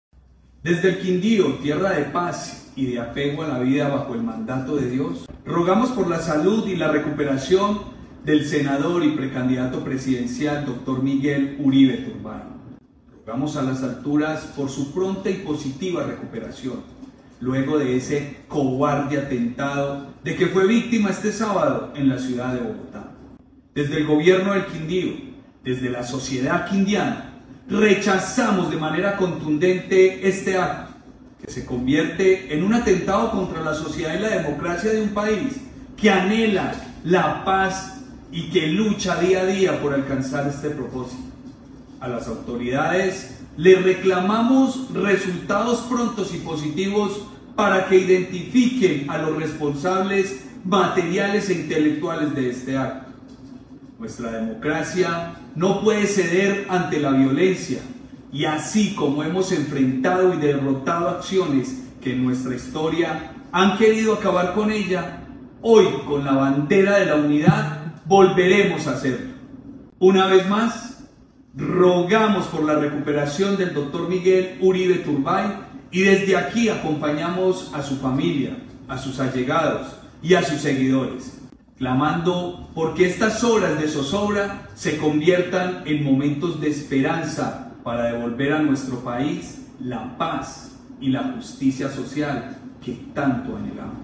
Gobernador del Quindío, Juan Miguel Galvis